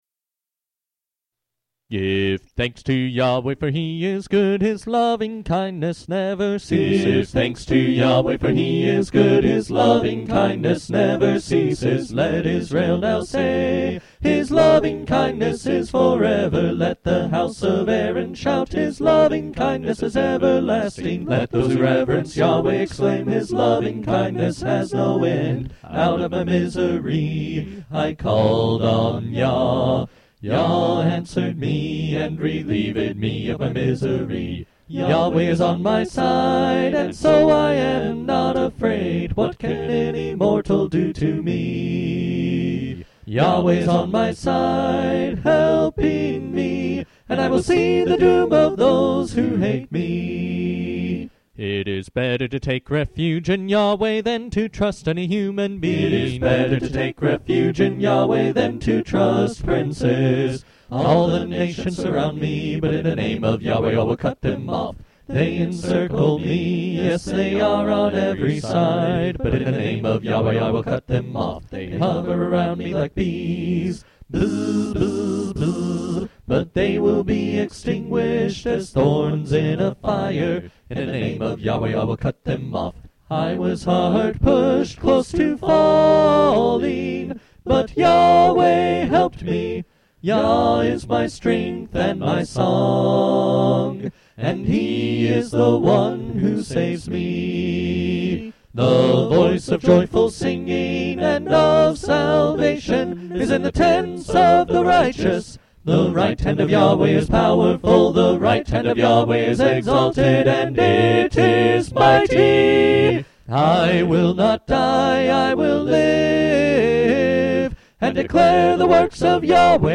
acapella vocal music